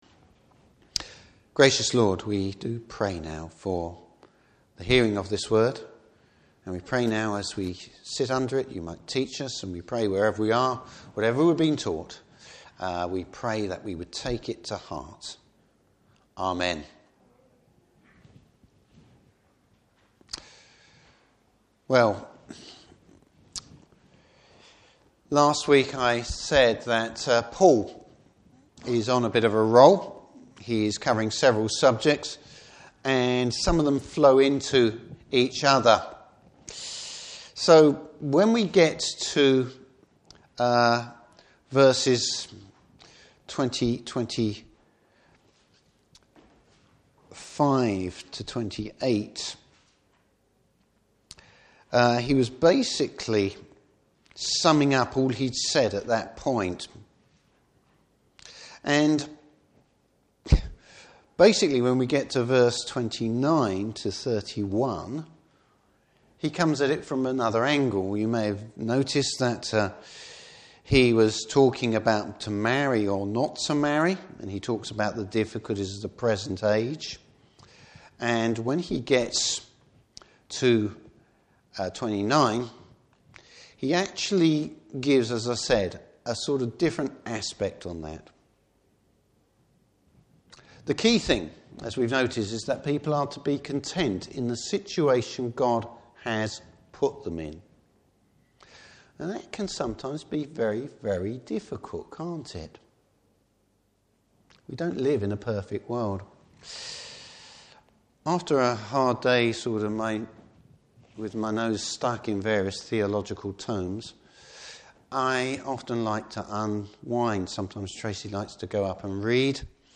Service Type: Morning Service How best to serve the Lord.